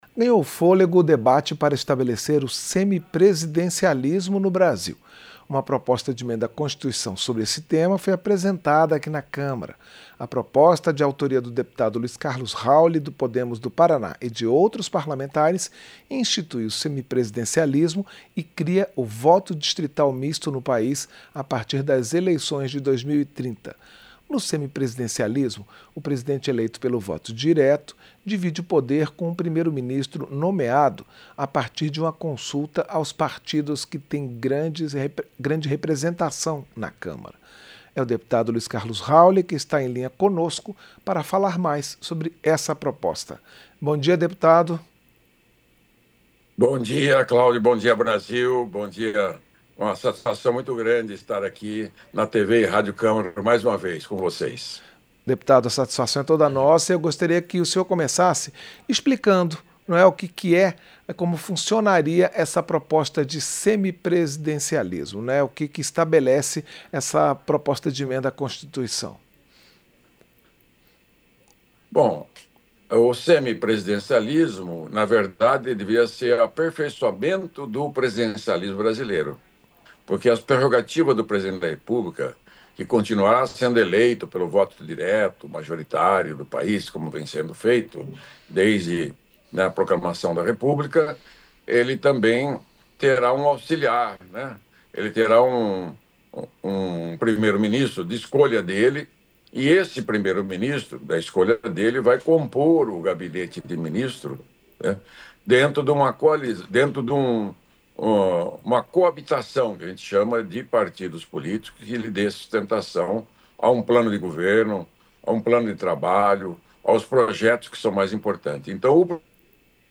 Entrevista - Dep. Luiz Carlos Hauly (Podemos-PR)